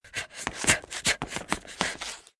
avatar_emotion_taunt.ogg